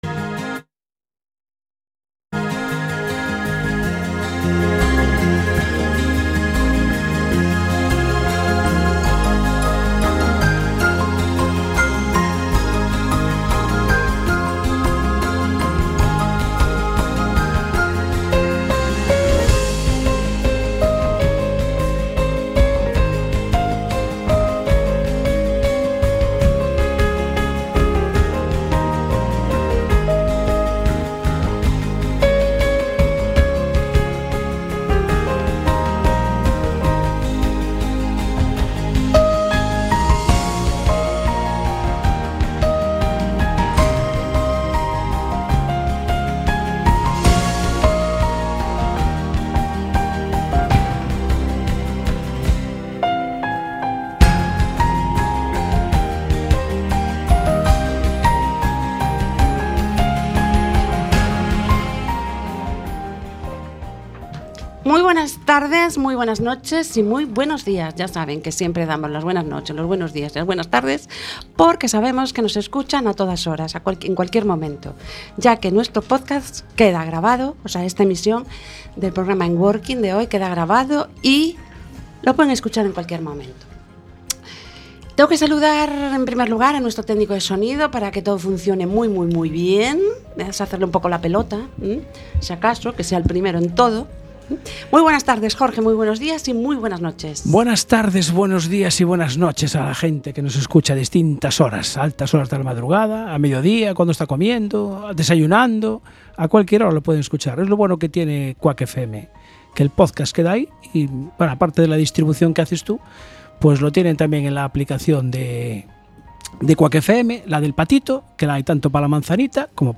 Este episodio se enmarca dentro de las colaboraciones del Programa de Radio Enworking con la UDC Saudable .